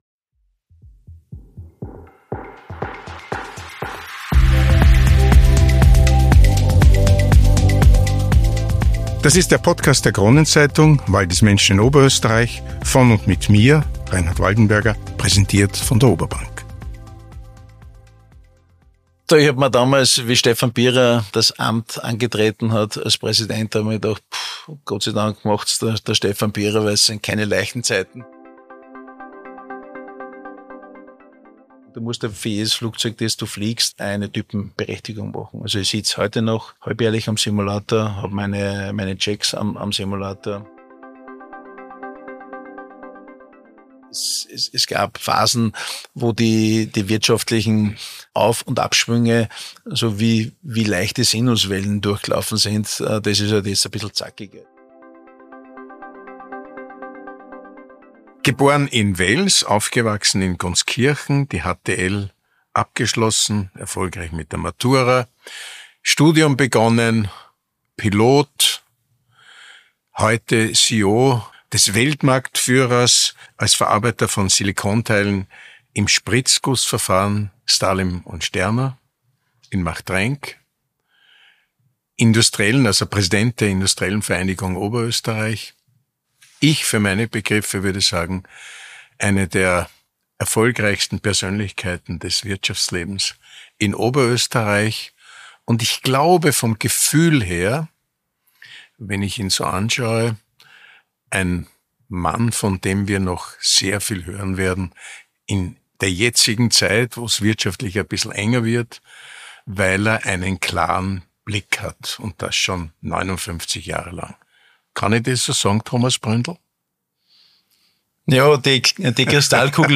Ein Gespräch über Disziplin, Verantwortung und den Mut, neue Wege zu gehen - vom Linienpiloten zum erfolgreichen Industriekapitän. Themen dieser Folge: - Vom Maschinenbaustudenten zum Linienpiloten und schließlich zum CEO eines Weltmarktführers.